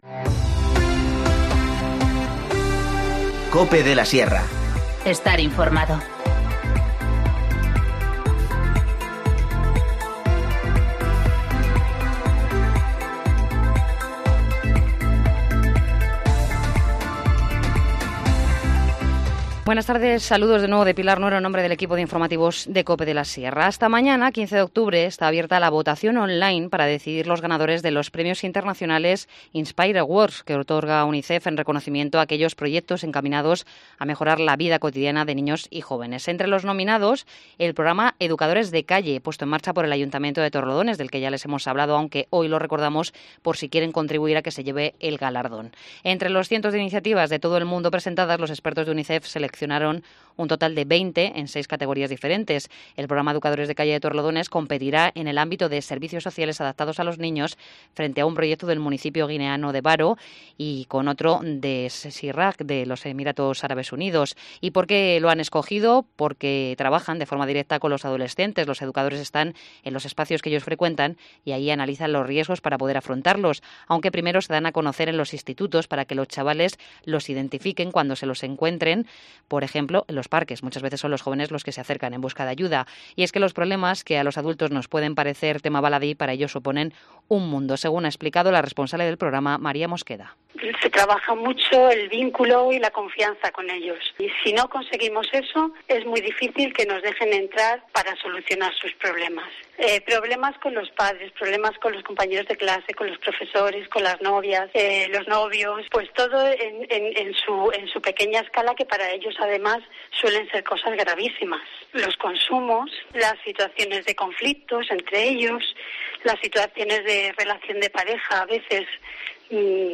Informativo Mediodía 14 octubre 14:50h